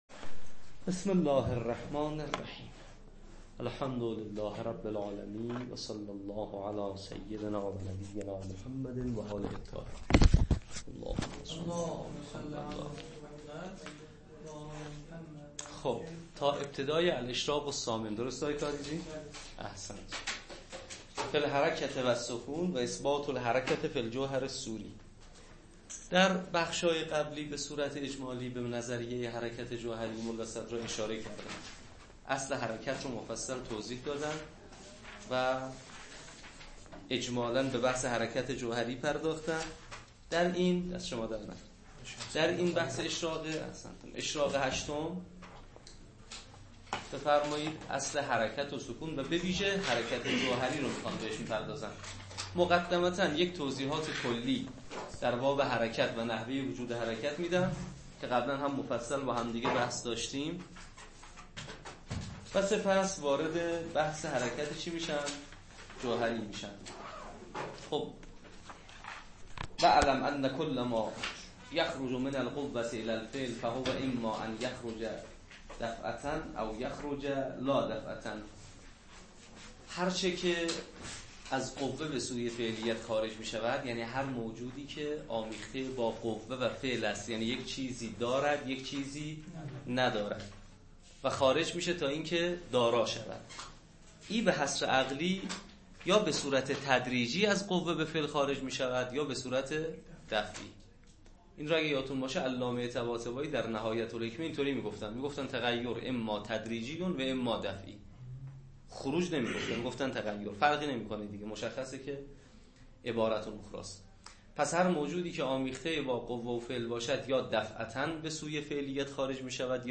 شواهد الربوبیه تدریس